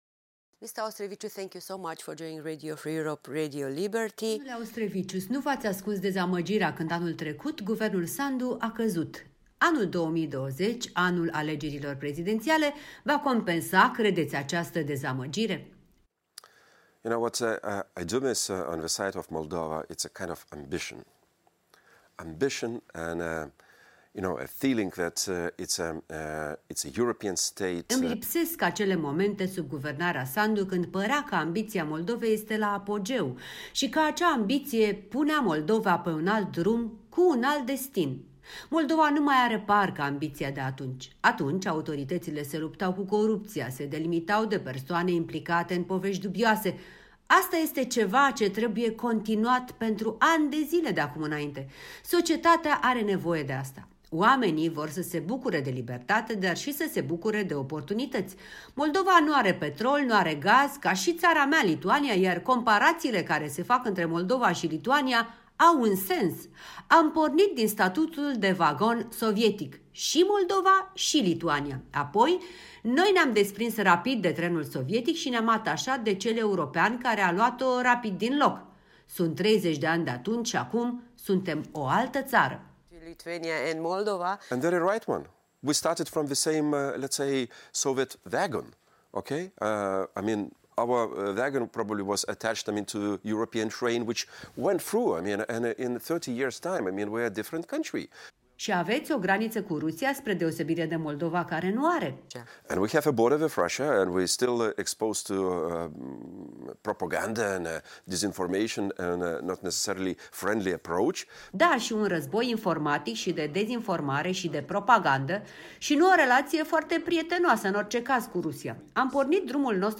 Interviu cu un eurodeputat lituanian.
Interviu cu Petras Auštrevičius